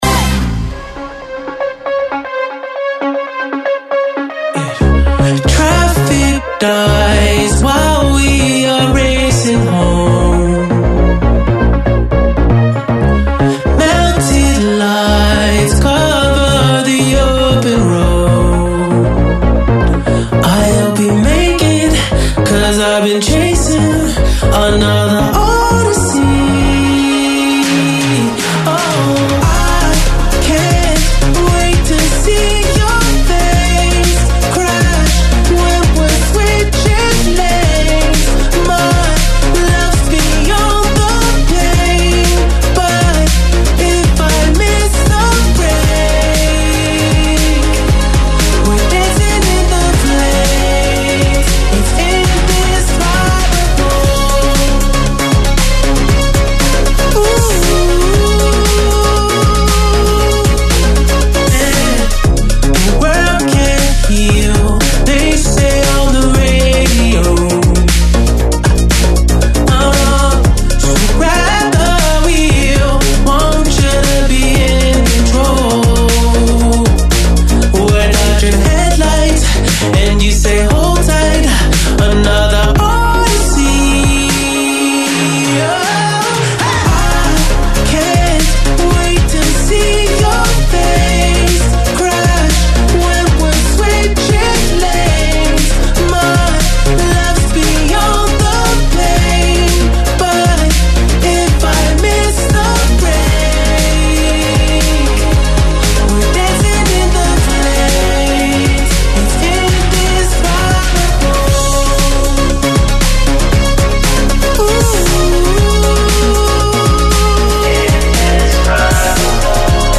COLLEGAMENTI IN DIRETTA CON I CANALI TV ALL NEWS, OSPITI AL TELEFONO DAL MONDO DELLO SPETTACOLO, DELLA MUSICA, DELLA CULTURA, DELL’ARTE, DELL’INFORMAZIONE, DELLA MEDICINA, DELLO SPORT E DEL FITNESS.